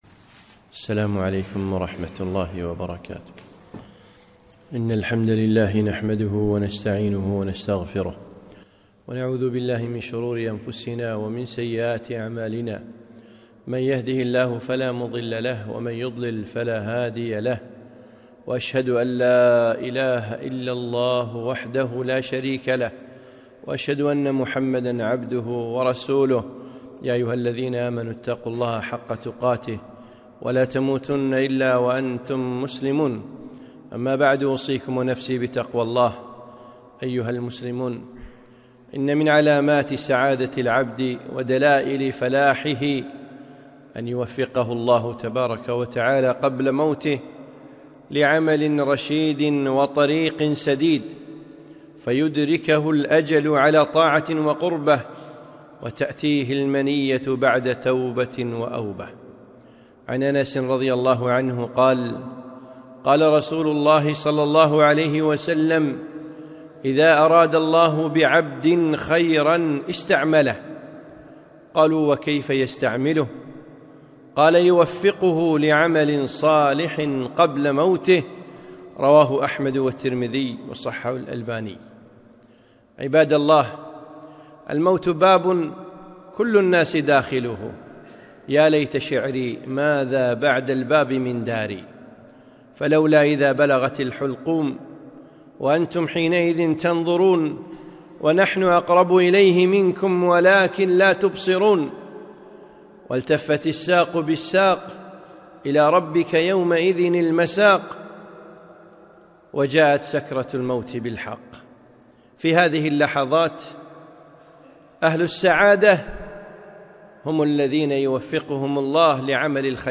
خطبة - الأعمال بالخواتيم